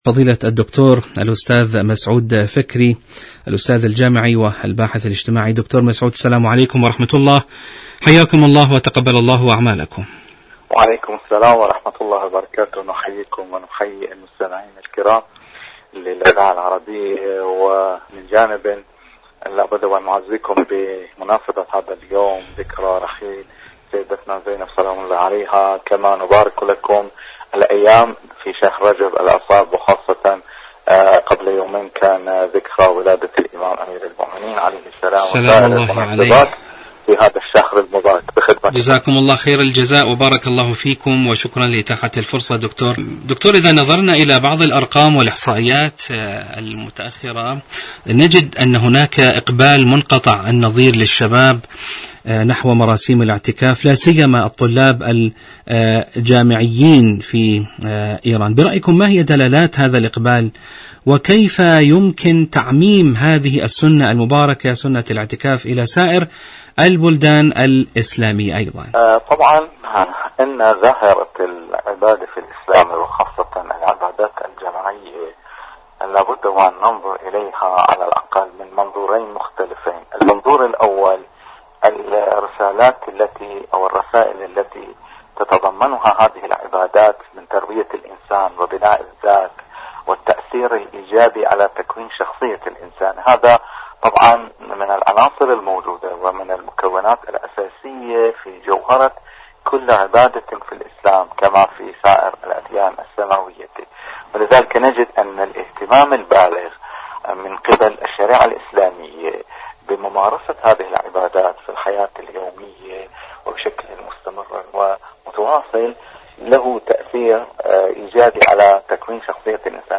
مقابلات برامج إذاعة طهران العربية برنامج دنيا الشباب الشباب الاعتكاف شهر رجب سنة الاعتكاف الشباب المعتكف مقابلات إذاعية دنيا الشباب شاركوا هذا الخبر مع أصدقائكم ذات صلة إشكالية القراءة عند النساء، ما هي ولماذا؟..